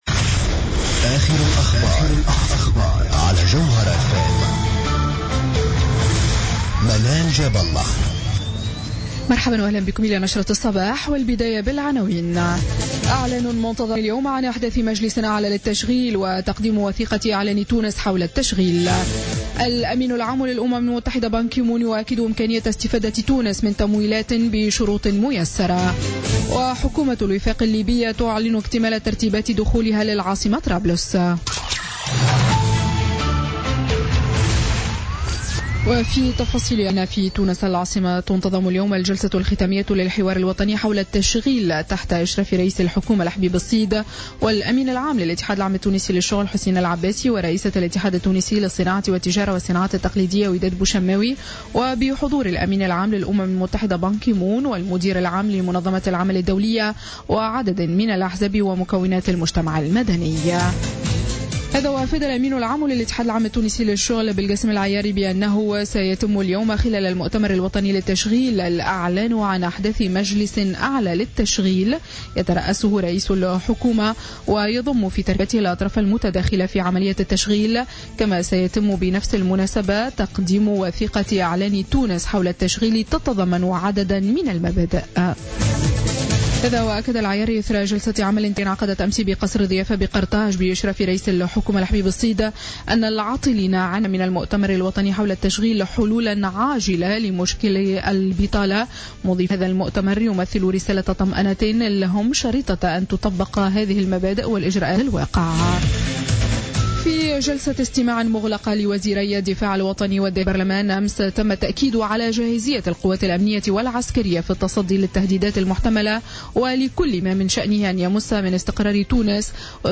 نشرة أخبار السابعة صباحا ليوم الثلاثاء29 مارس 2016